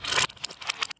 DoorLocking.ogg